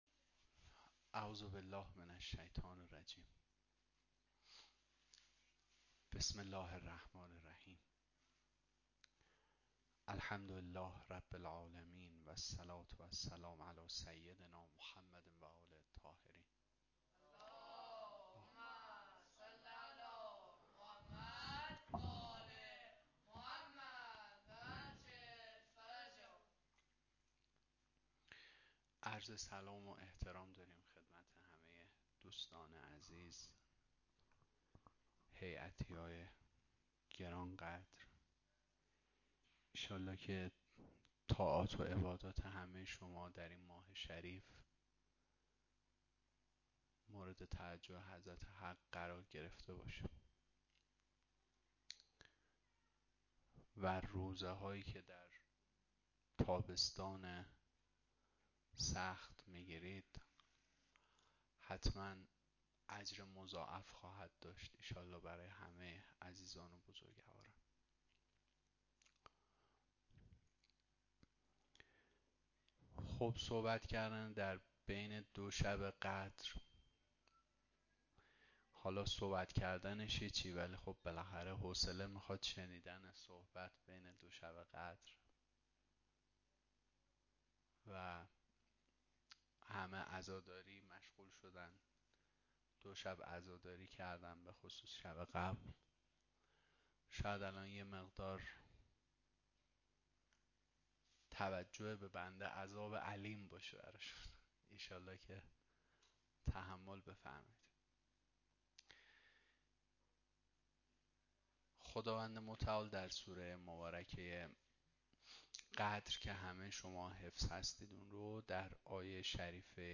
شهادت امام علي (ع)-سخنراني
01-sham-shahadat-hazrat-ali-93-sokhanrani.mp3